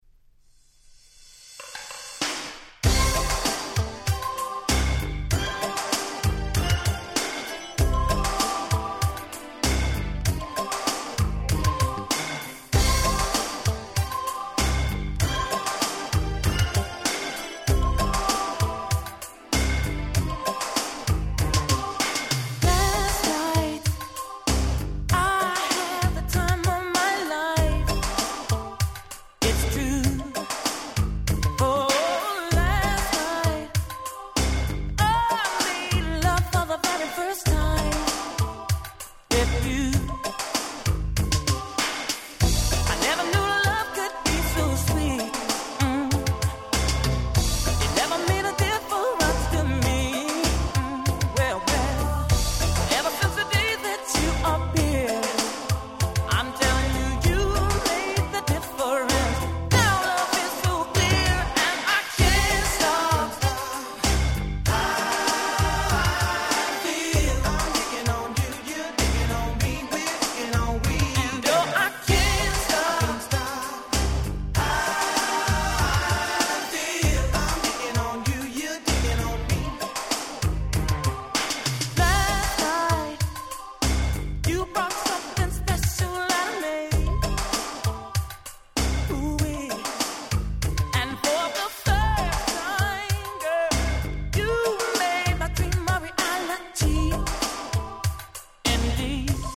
89' Big Hit R&B LP !!